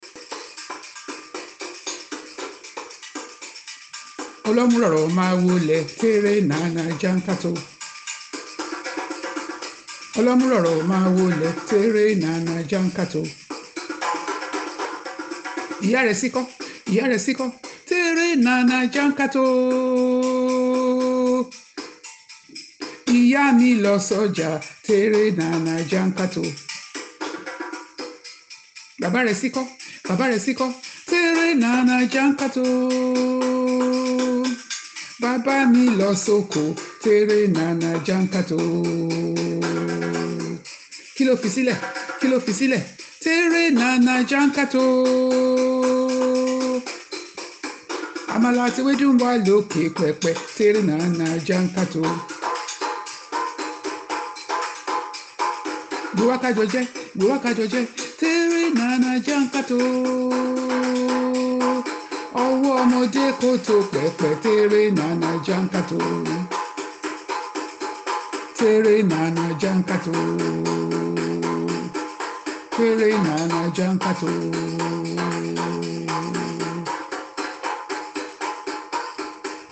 The question and answer sing-song continued, and that was how a song was made up by the strange woman and Saraya, as follows: